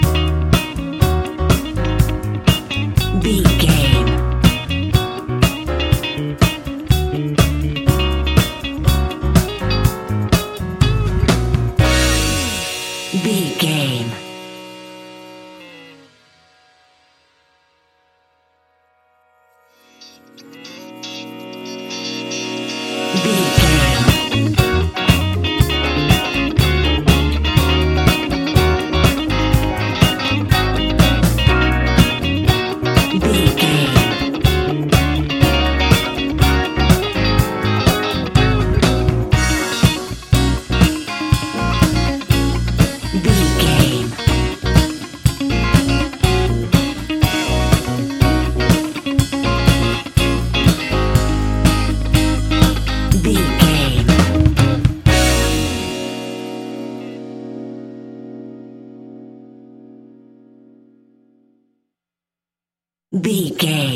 Ionian/Major
F♯
house
electro dance
synths
techno
trance
instrumentals